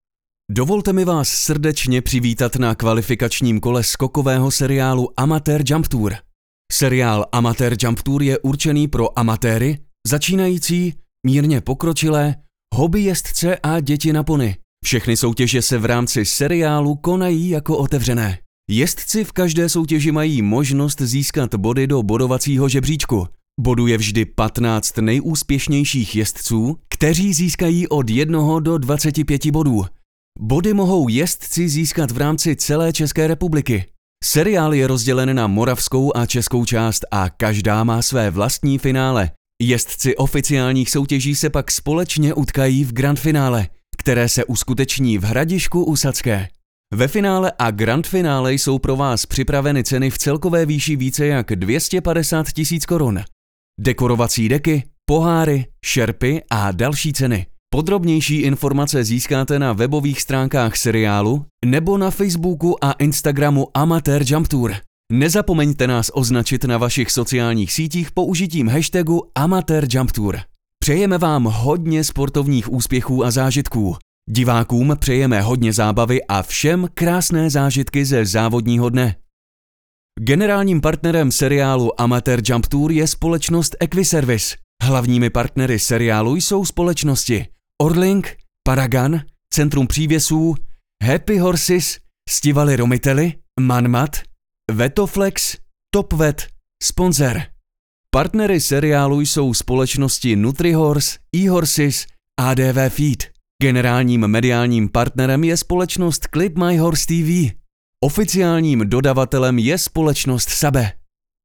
Obdržíte kvalitní zvukovou stopu - voiceover (wav/mp3), vyčištěnou od nádechů a rušivých zvuků, nachystanou pro synchronizaci s vaším videem.
Mužský voiceover do Vašeho videa (Voiceover / 90 sekund)